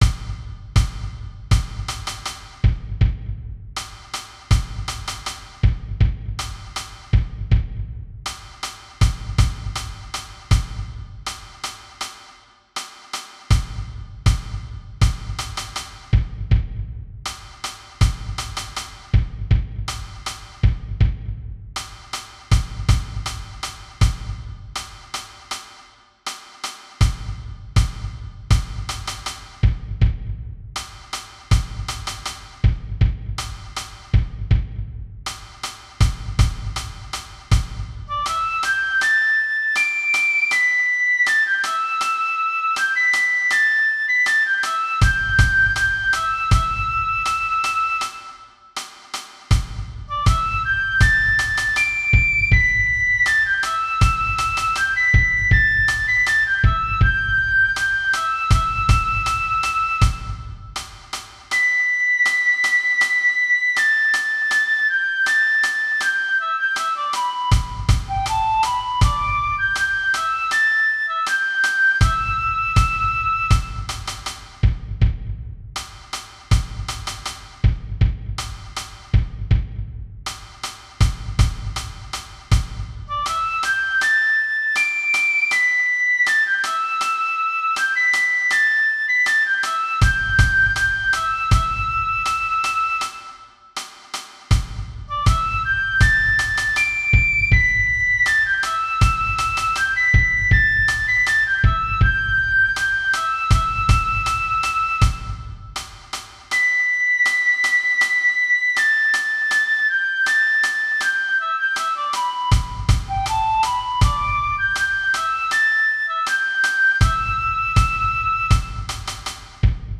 みつぼし踊り　舞台用太鼓音源